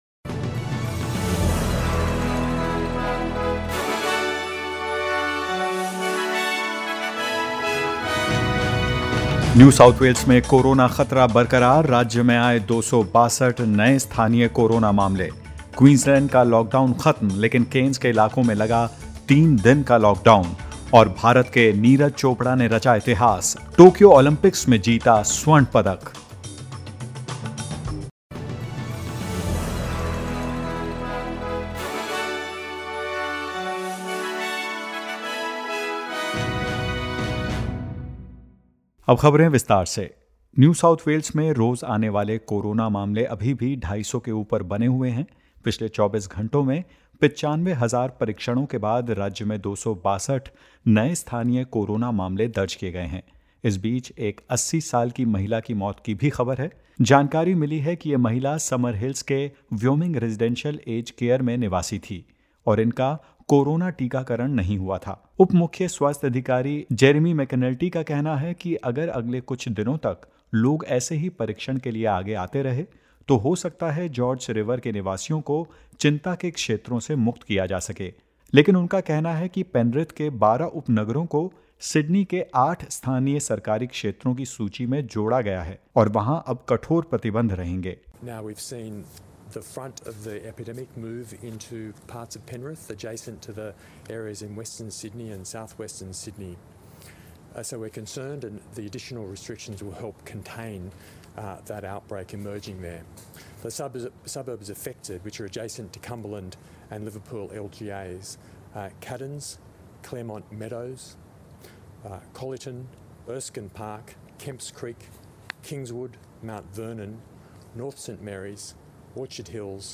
In this latest SBS Hindi News bulletin of Australia and India: Cairns and Yarrabah to enter into a three-day lockdown from 4 pm today; Victoria reports 11 new locally acquired COVID-19 cases and more.